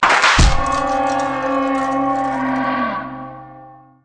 playerdies_5.ogg